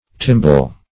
Search Result for " timbal" : The Collaborative International Dictionary of English v.0.48: Timbal \Tim"bal\, n. A kettledrum.